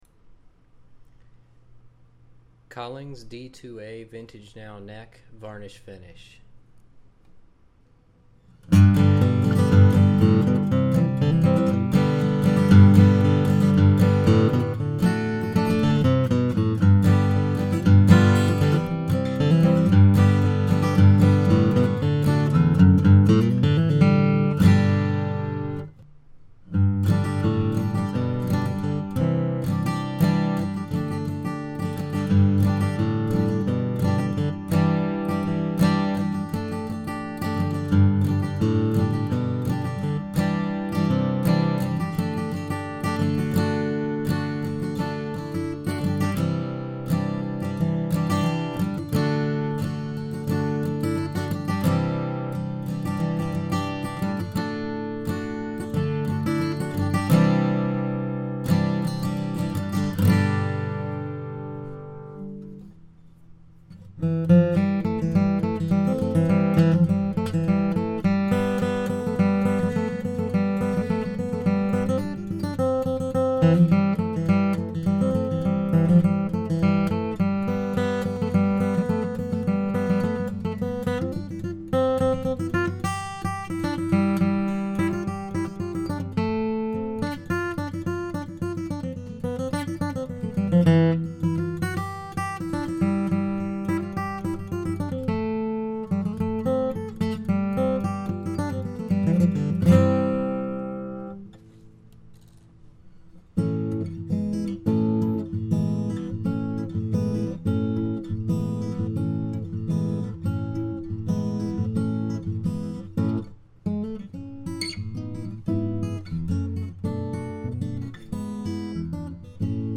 Soundboard: Adirondack Spruce
Body Style: Dreadnought
This dread exhibits the clean, powerful Collings tone with its distinctive bass and microphone-friendly character...and a little something extra from the varnish finish. It's difficult to describe, but there's a noticeable openness to the sound that's not often present in new Collings guitars.